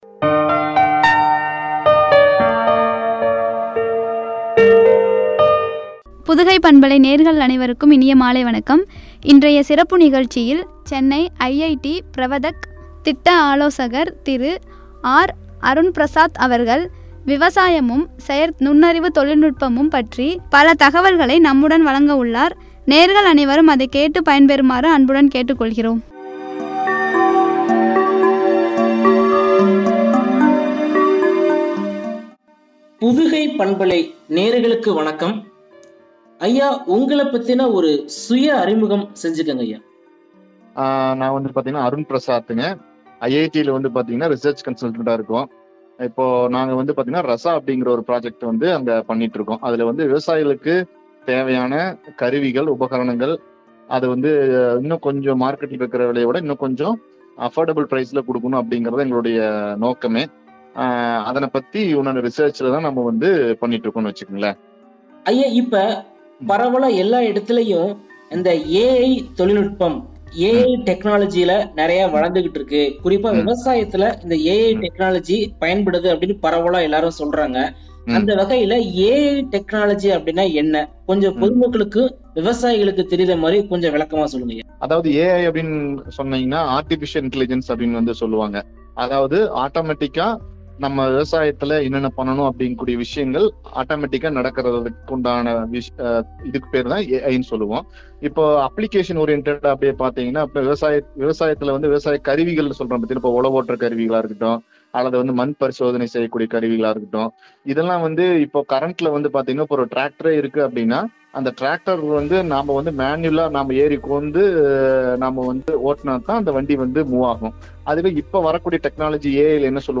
தொழில்நுட்பமும் குறித்து வழங்கிய உரையாடல்.